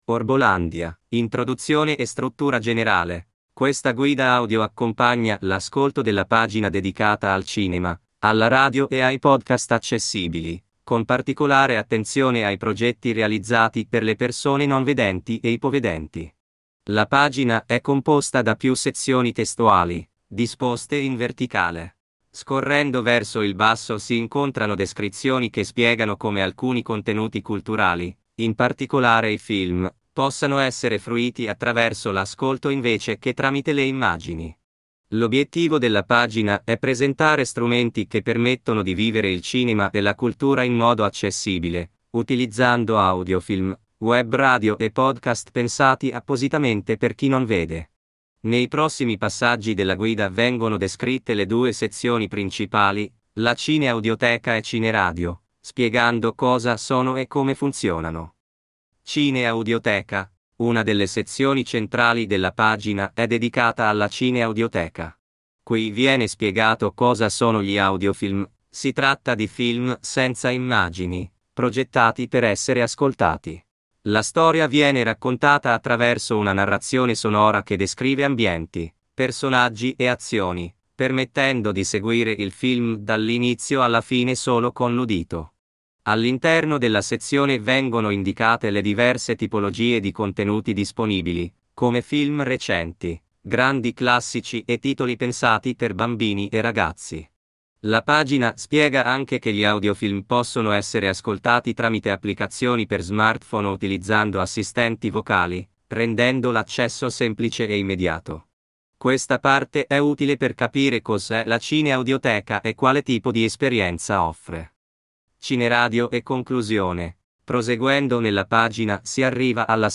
una guida audio veloce, pensata per l’ascolto;